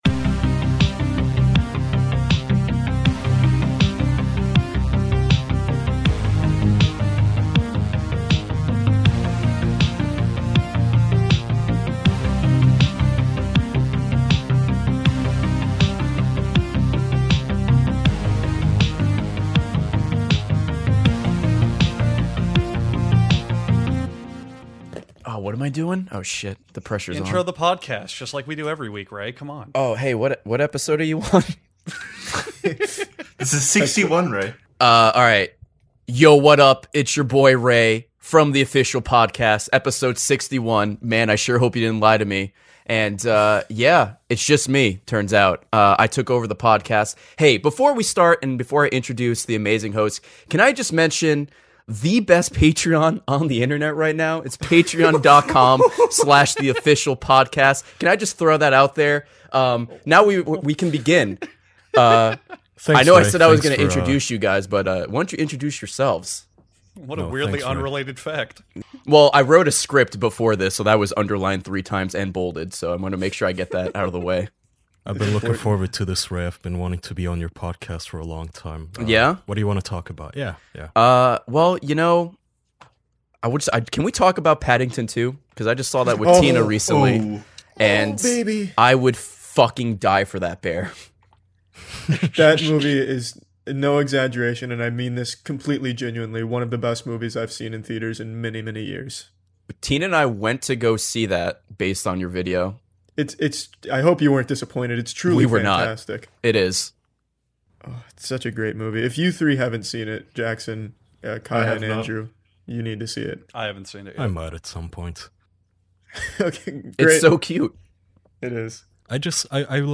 Five close man friends gather around to disrespect their marrages.
We've done our best to fix the issue in these recordings, but it is noticeable.